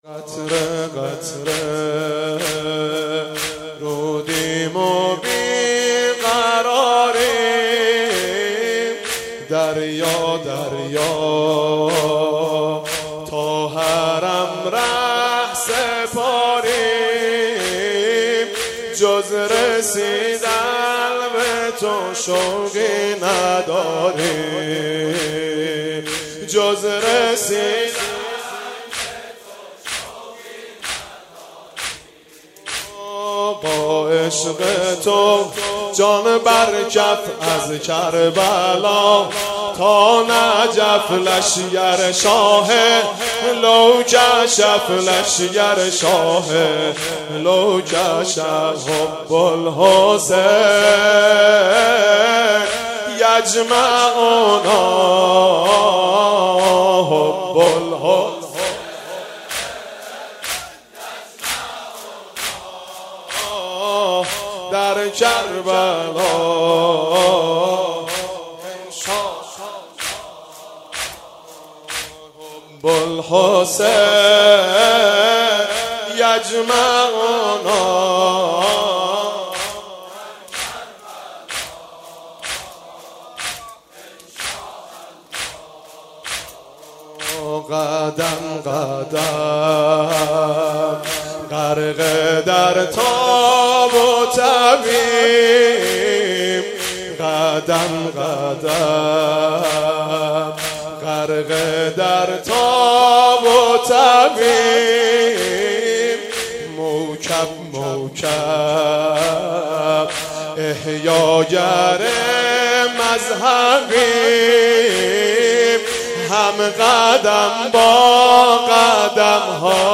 زمینه - قطره قطره